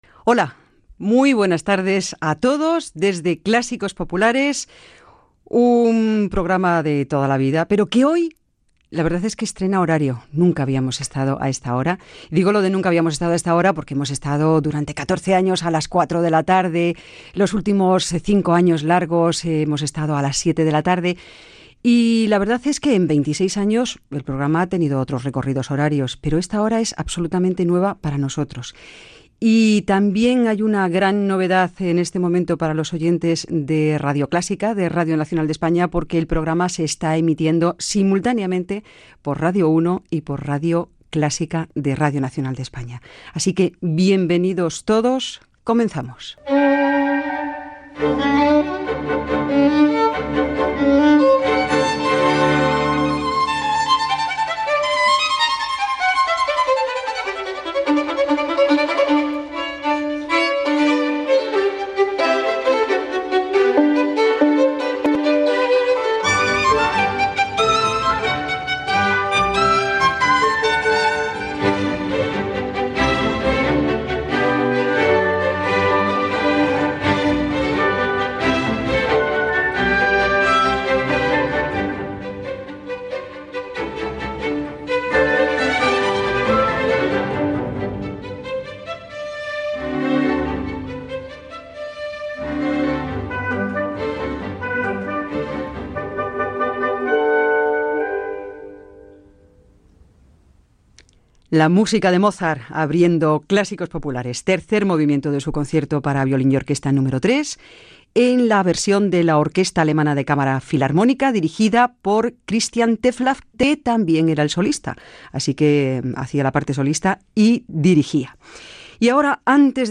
Comentari sobre el canvi d'horari del programa i les dues emissores que l'emeten, música, comentari del tema que s'ha escoltat, intervenció telefònica de Fernando Argenta
Musical